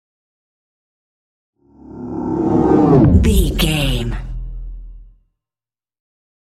Whoosh deep large
Sound Effects
dark
futuristic
intense
tension